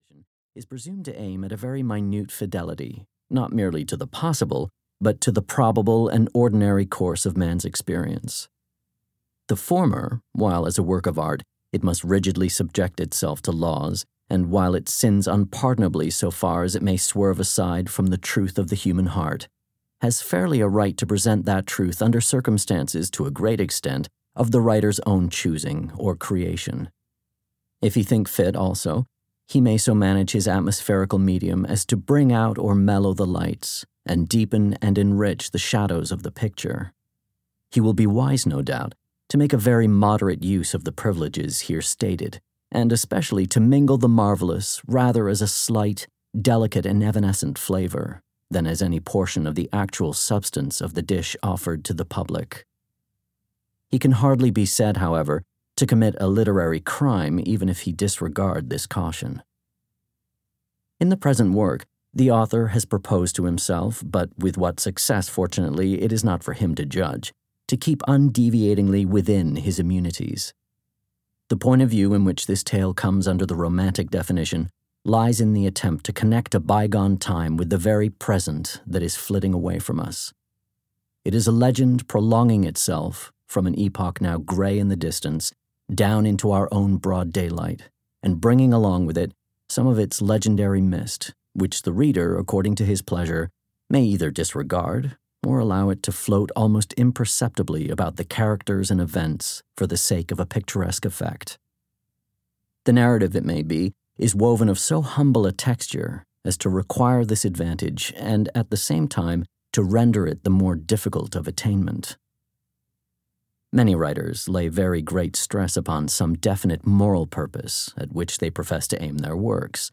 The House of the Seven Gables (EN) audiokniha
Ukázka z knihy